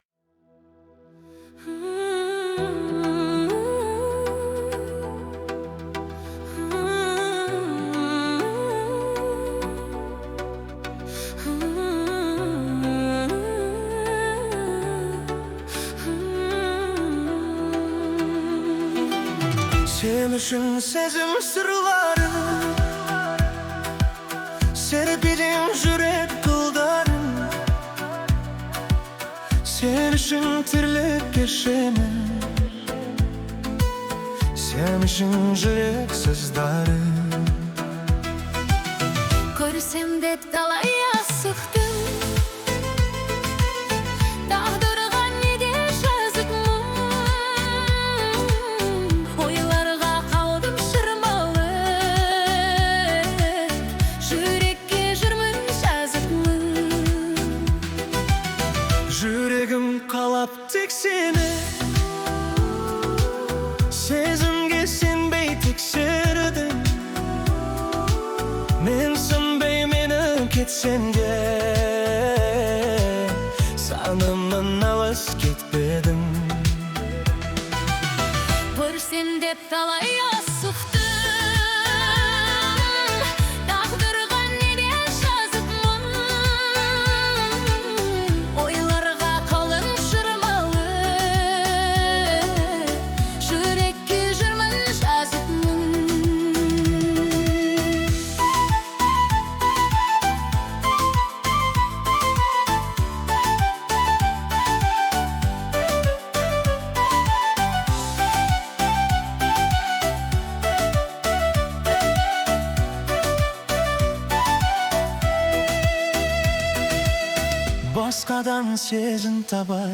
Казахский хит от нейросети